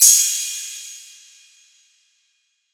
Cymbal 5 [ Luger P08 ].wav